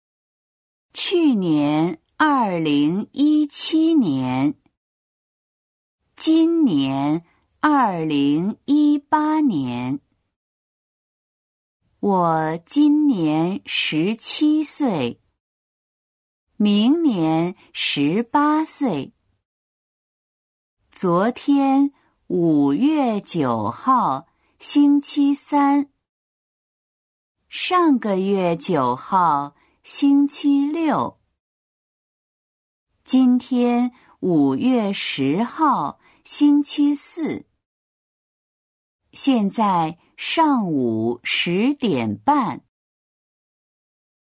音読